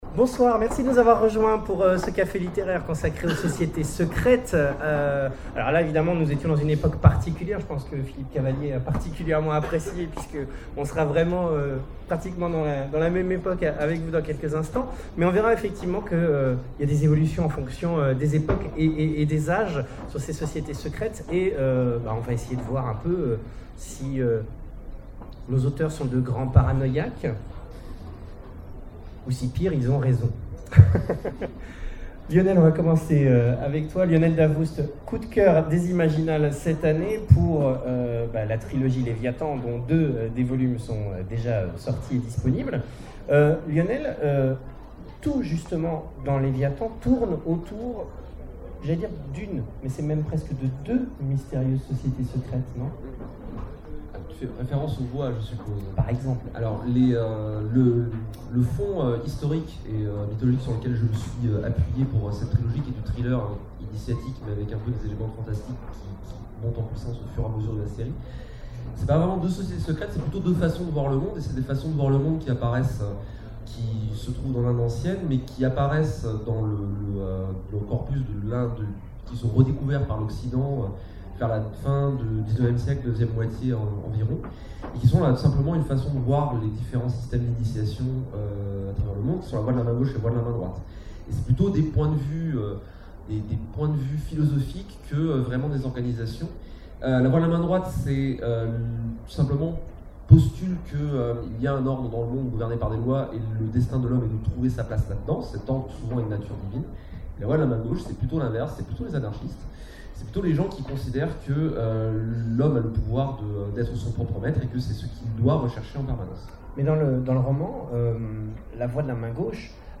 Imaginales 2012 : Conférence Sociétés secrètes...